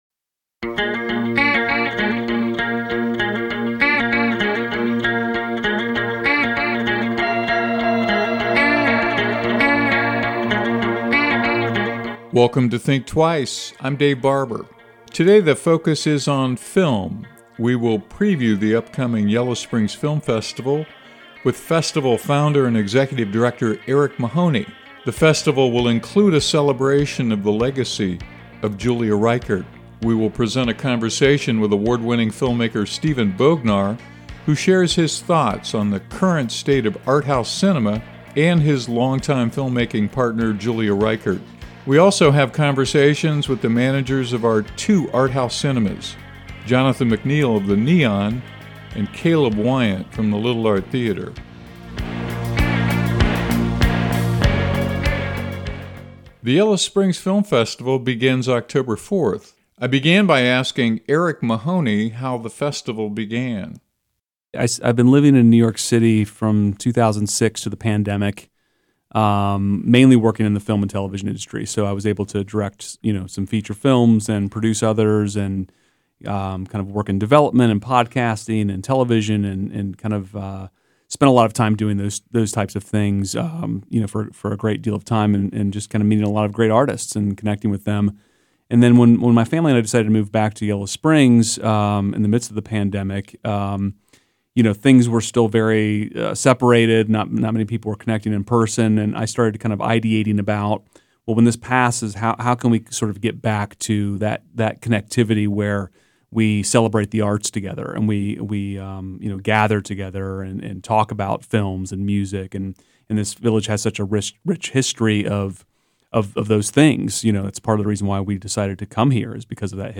Also, a conversation about the status of art-house-cinemas.